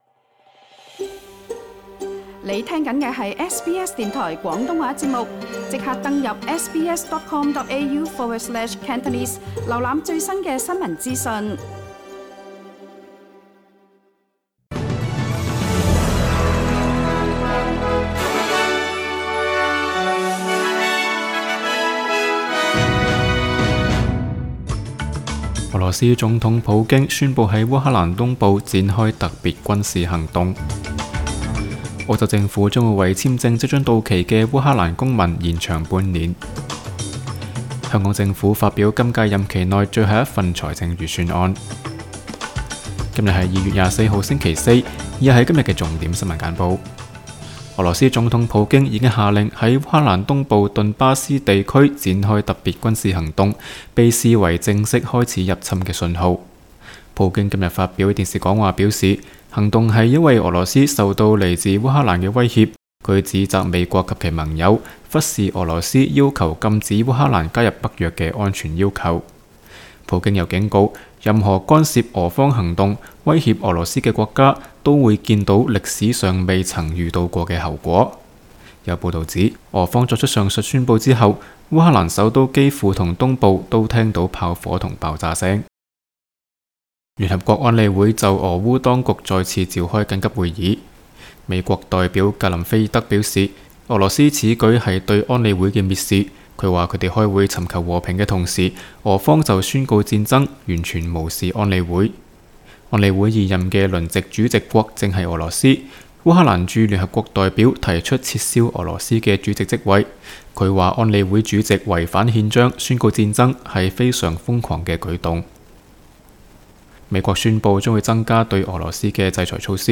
SBS 新聞簡報（2月24日）
請收聽本台為大家準備的每日重點新聞簡報。